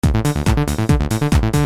Qui potrete trovare files in formato .rbs e .wav da usare in modo loop, per poterli edittare, trasformare, oppure elaborare in sequenza con programmi appropriati, che sicuramente, gli appassionati a questo tipo di lavoro conoscono molto bene.
Sequence mp3